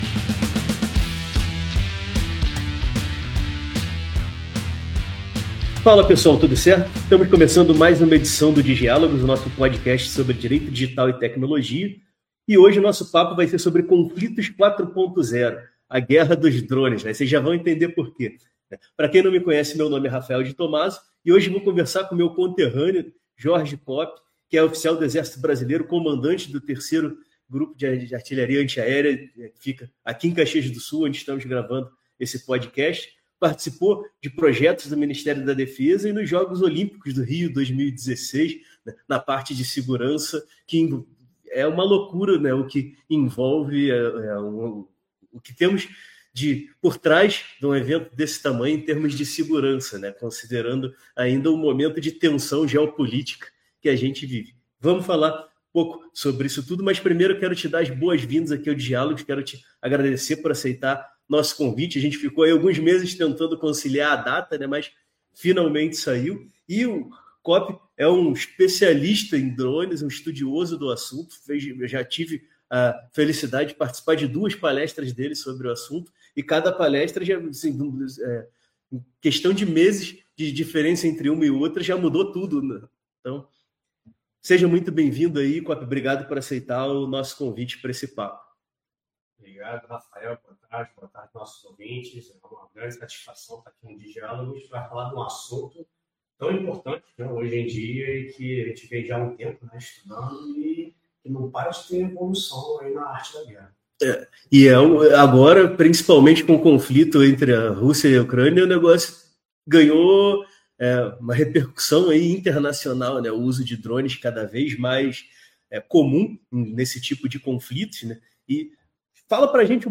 Bate-papo informal sobre Direito Digital e Tecnologia.